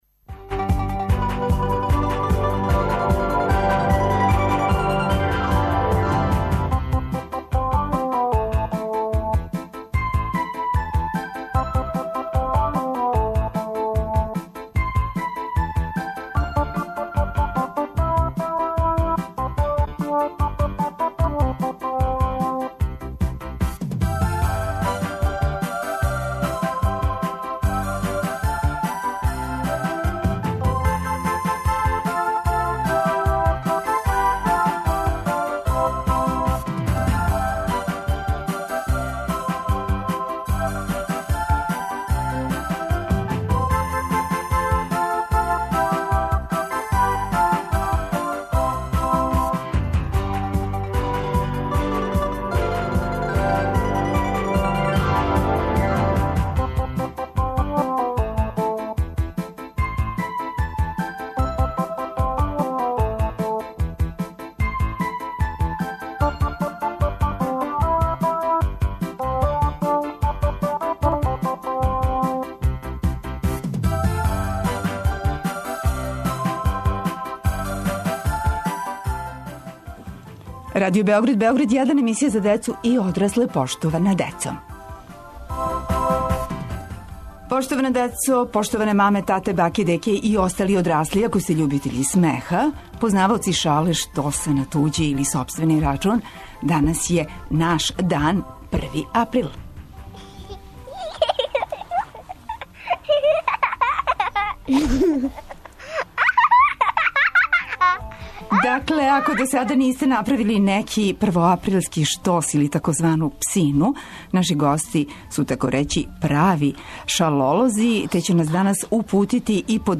Емисија 'Поштована децо' ове недеље биће у знаку шале и смеха. Са децом - гостима у студију размењујемо идеје за мале првоаприлске подвале.